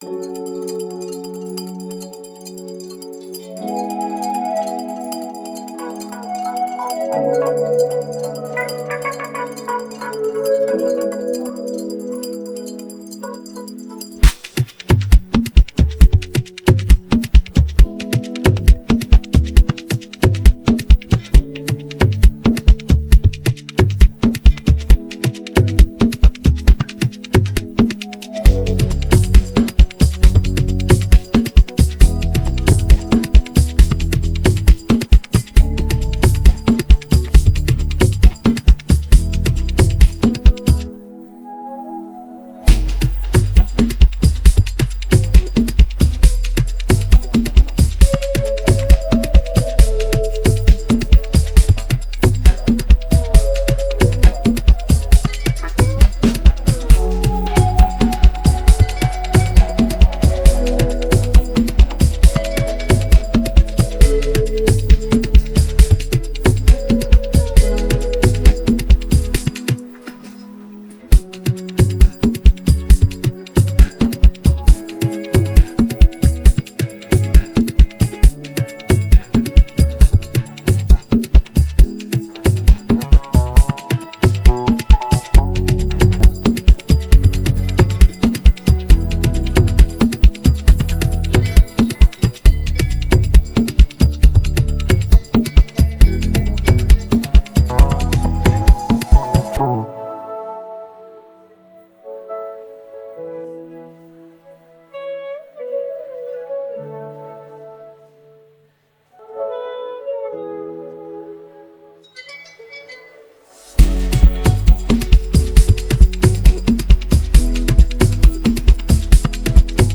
Afro trapAfrobeats